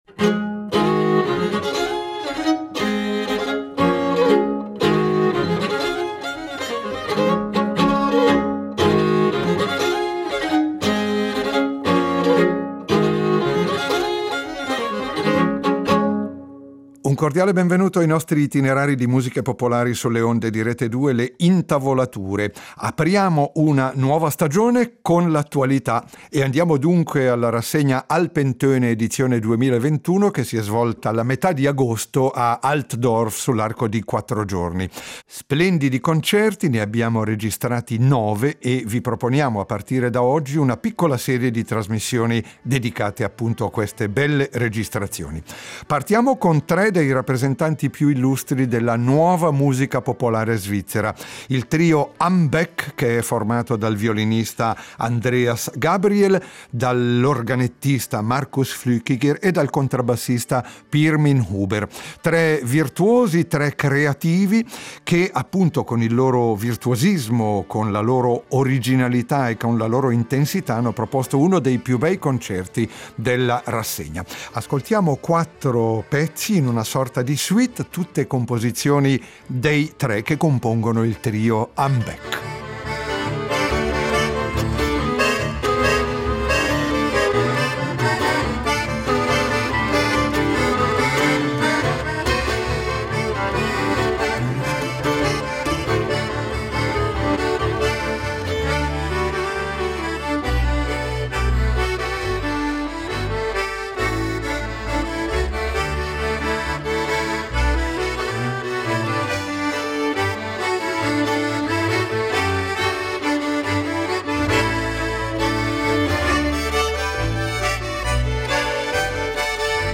nuova musica dell’arco alpino